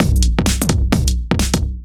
OTG_Kit 1_HeavySwing_130-C.wav